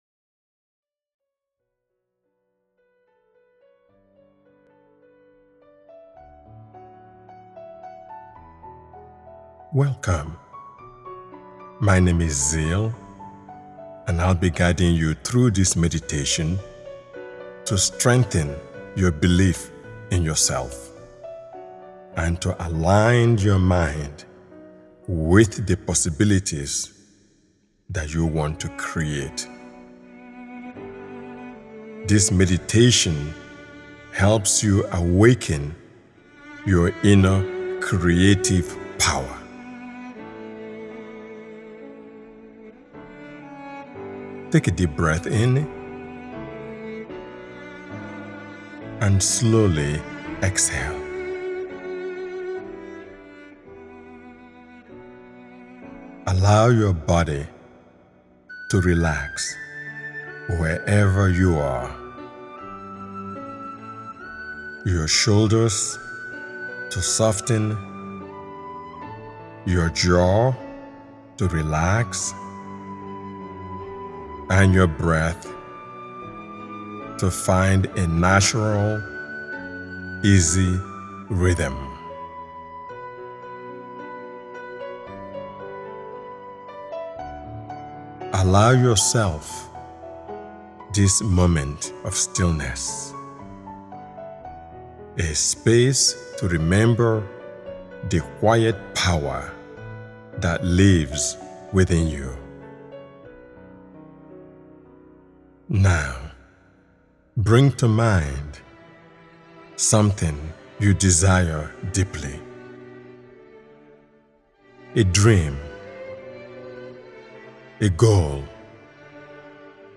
This inspiring guided meditation offers a gentle yet powerful pathway into clarity, confidence, and purposeful creation.